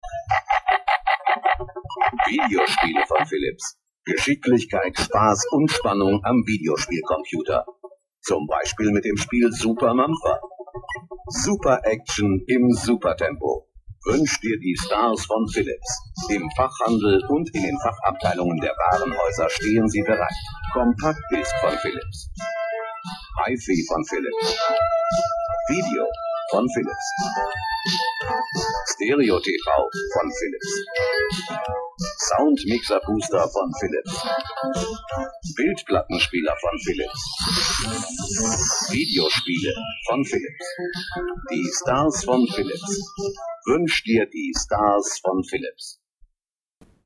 Great audio commercial in German.
philips_commercial.mp3